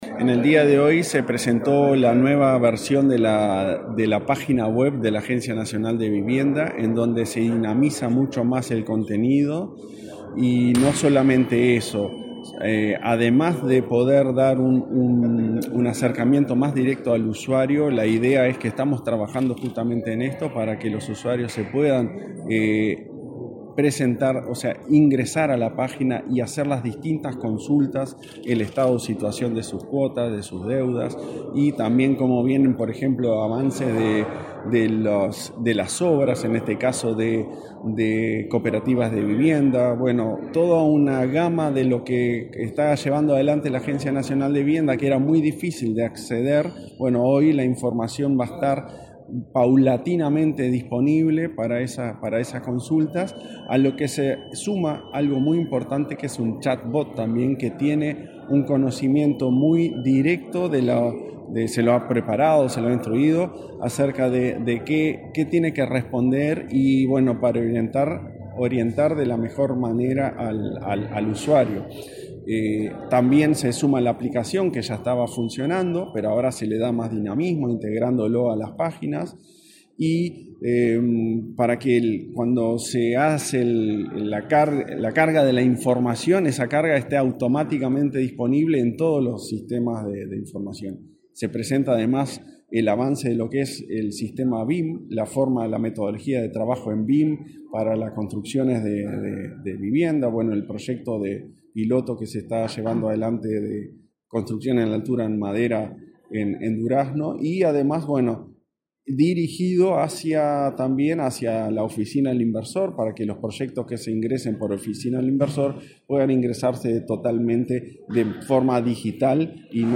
Declaraciones del presidente de ANV, Klauss Mill
Declaraciones del presidente de ANV, Klauss Mill 12/12/2023 Compartir Facebook X Copiar enlace WhatsApp LinkedIn El presidente de la Agencia Nacional de Vivienda (ANV), Klauss Mill, dialogó con la prensa en la Torre Ejecutiva, luego de presentar la renovación del sitio web y la aplicación móvil de la institución, en el marco de una transformación digital.